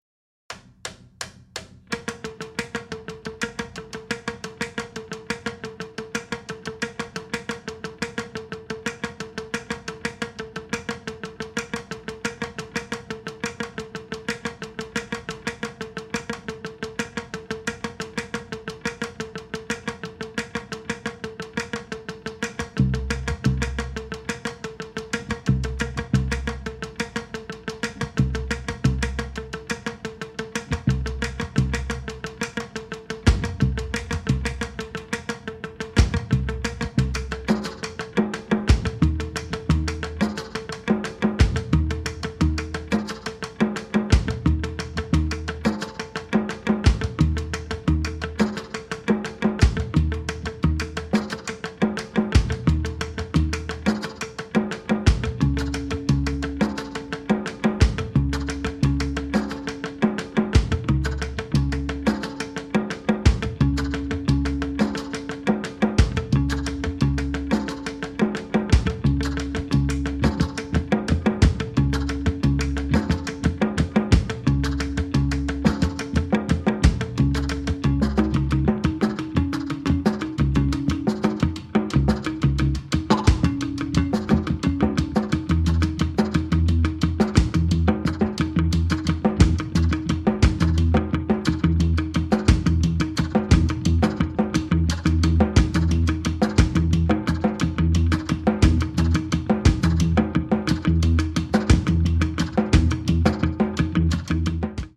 エキゾチックな風合いも感じられて◎！
聴いてて楽しいミニマル・ポリリズム集です！